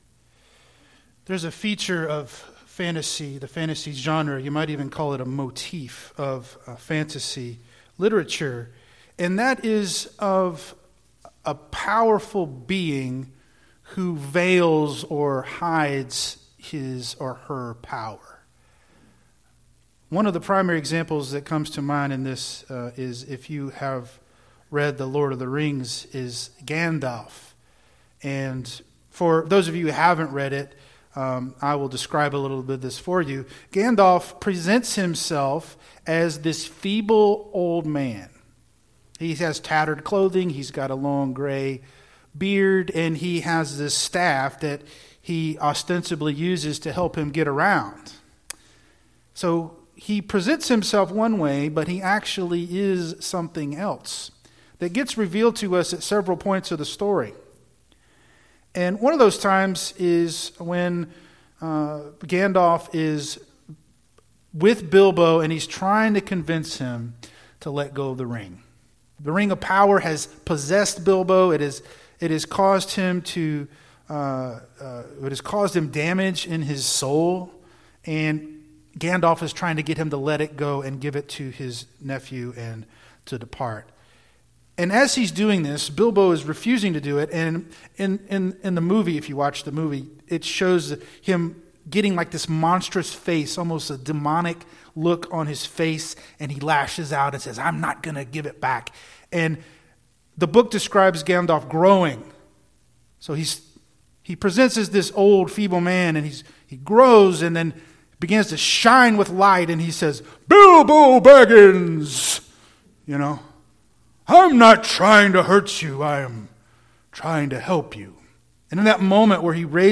The God We Worship: A Sermon on Psalm 100